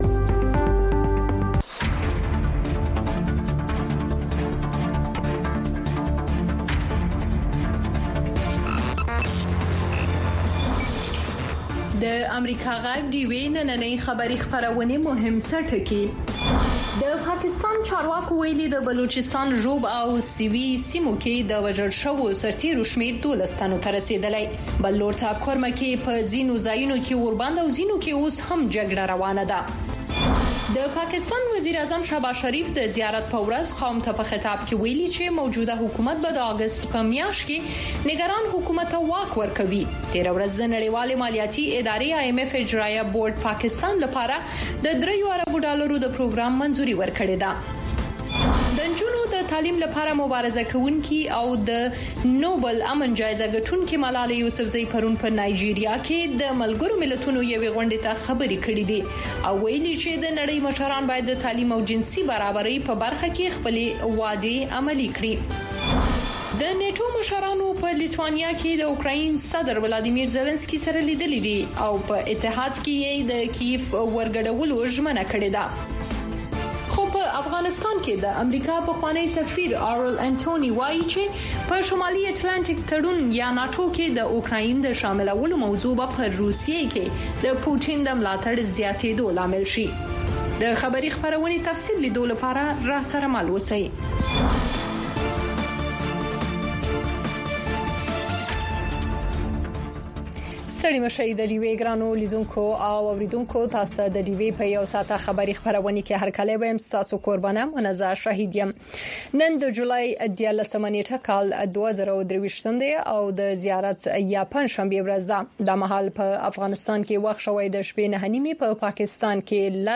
خبرونه
د وی او اې ډيوه راډيو ماښامنۍ خبرونه چالان کړئ اؤ د ورځې د مهمو تازه خبرونو سرليکونه واورئ.